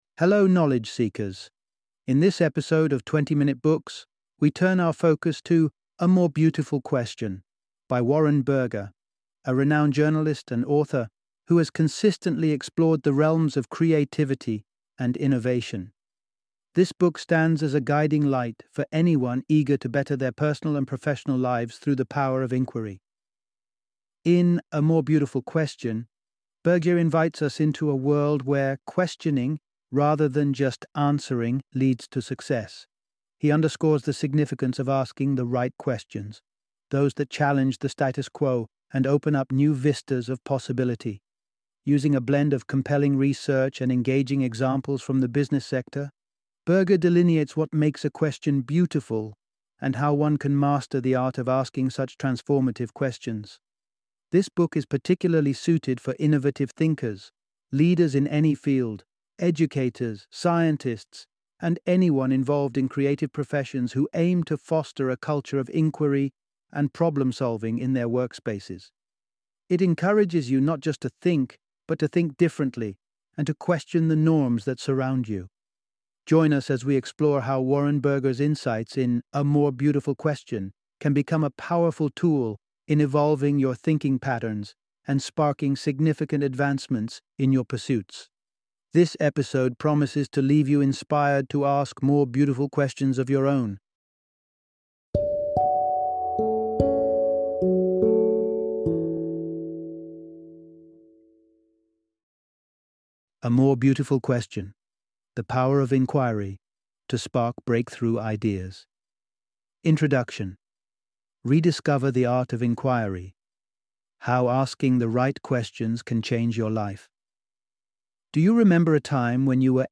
A More Beautiful Question - Audiobook Summary